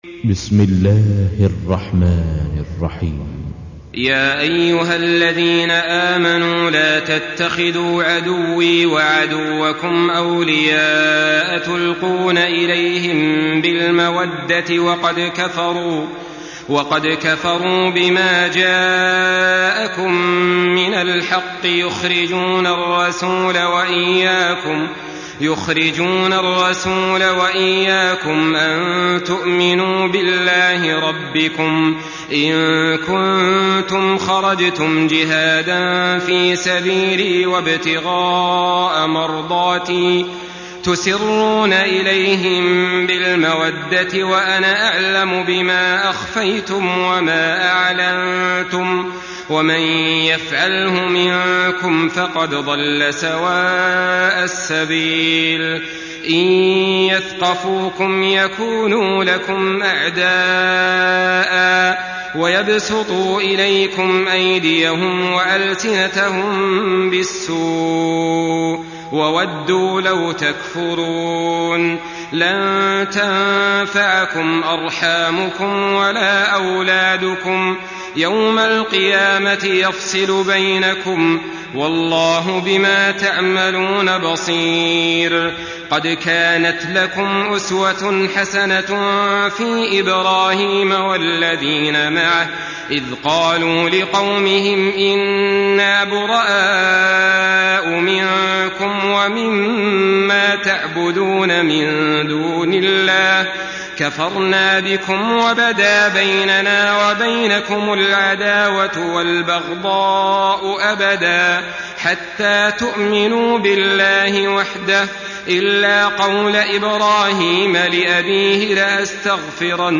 Surah Mümtehine MP3 by Saleh Al-Talib in Hafs An Asim narration.
Murattal Hafs An Asim